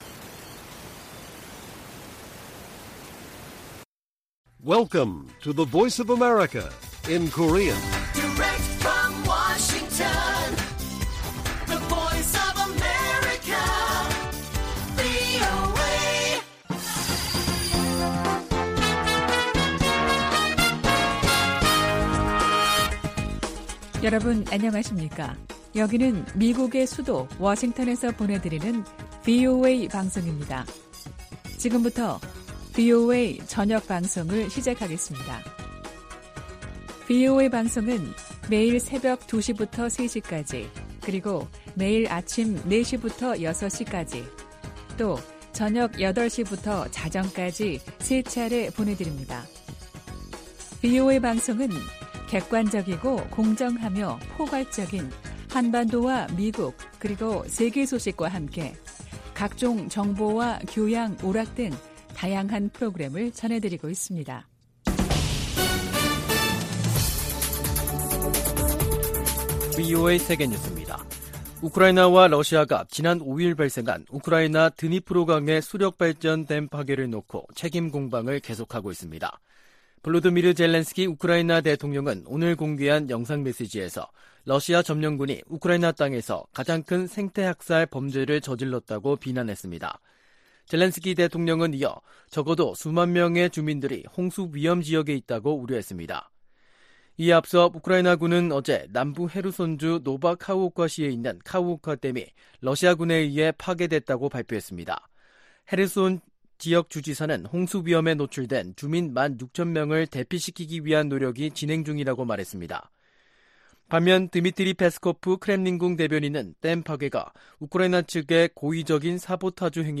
VOA 한국어 간판 뉴스 프로그램 '뉴스 투데이', 2023년 6월 7일 1부 방송입니다. 한국이 유엔 안전보장이사회 비상임이사국으로 다시 선출됐습니다.